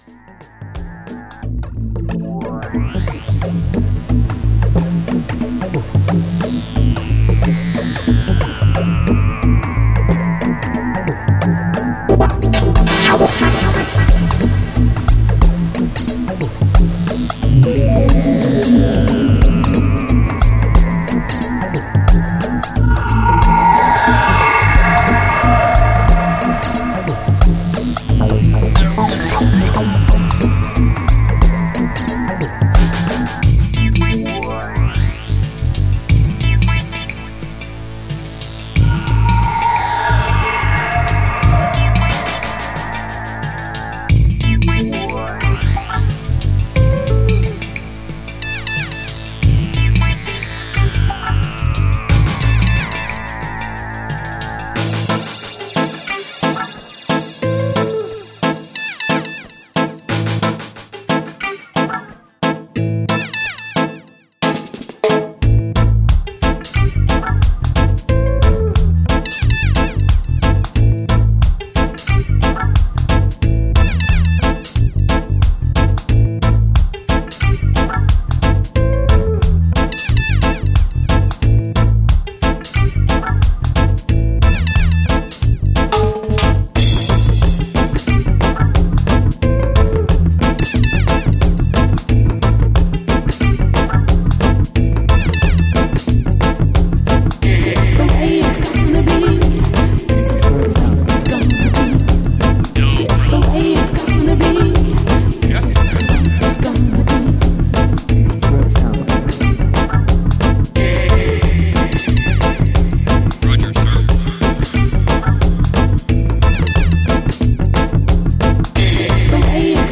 son home studio